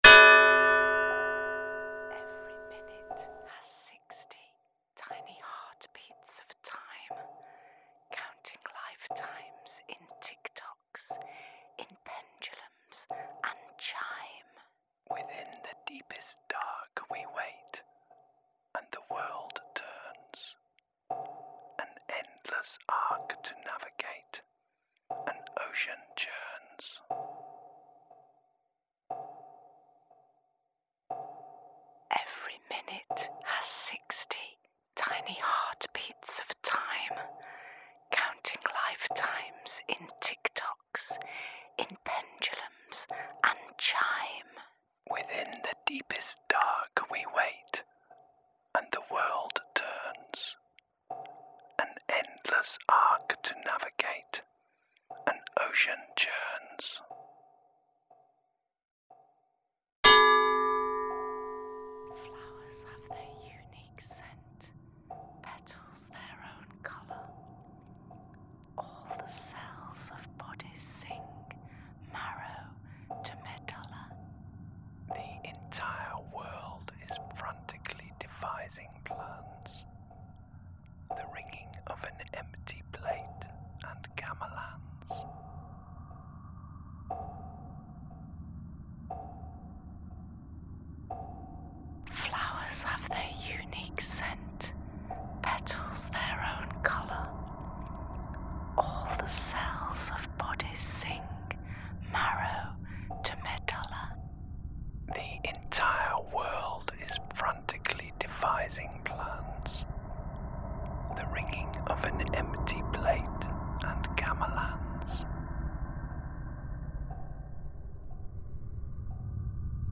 Long Division was commissioned by Oxford Contemporary Music and Oxford Botanic Garden for The Magic Hour event, September 2008.
Long_Division_Soundscape.mp3